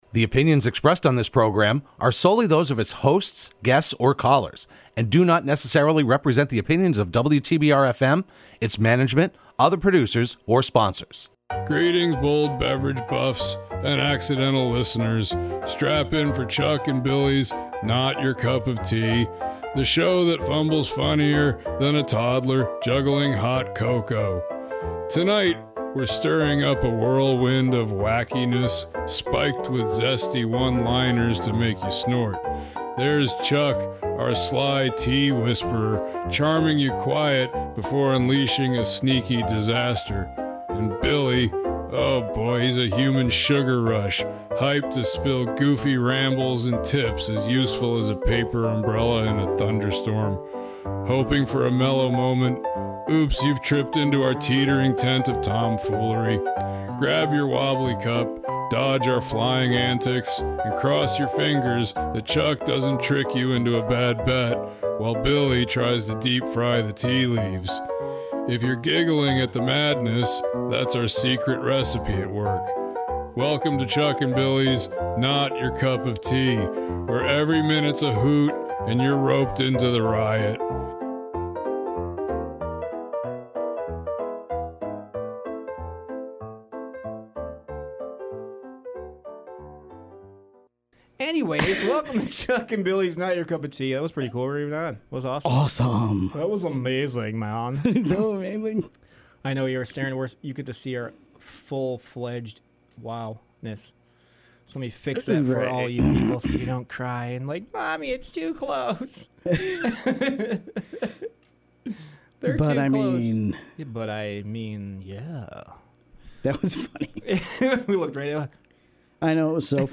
Broadcast live every Wednesday afternoon at 3:30pm on WTBR.